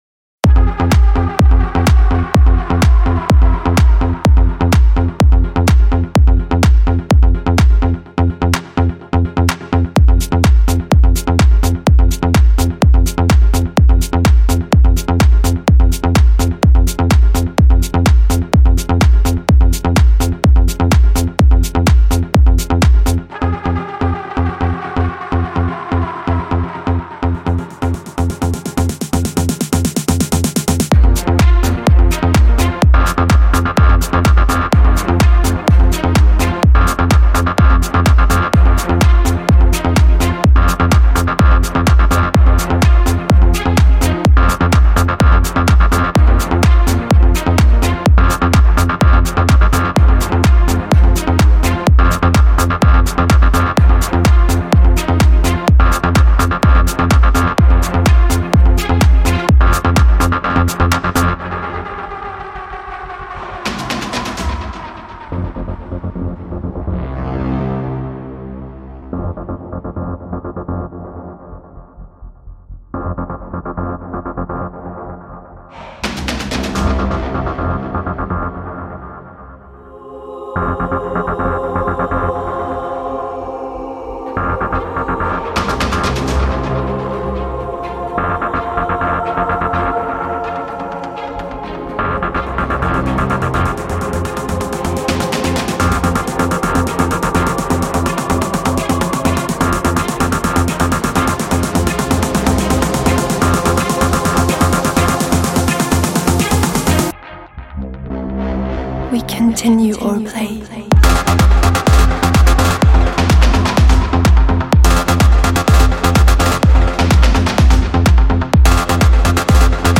Клубная музыка
техно музыка